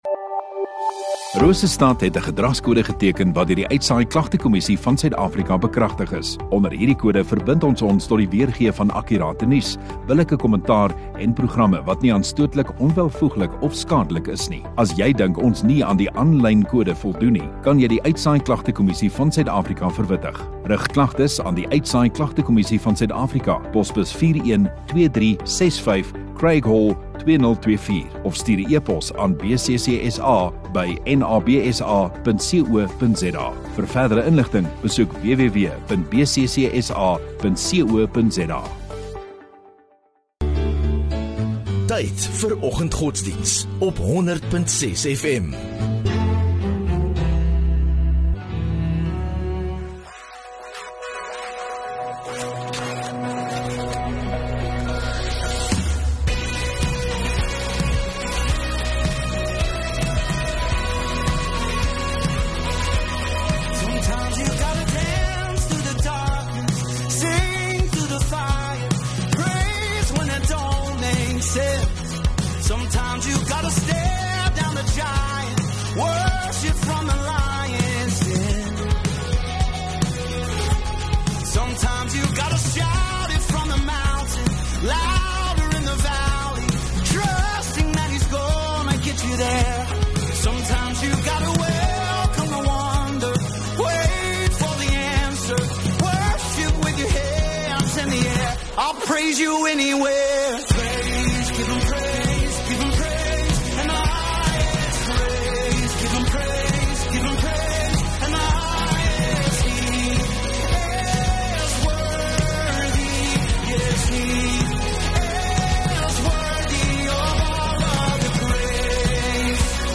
7 Feb Vrydag Oggenddiens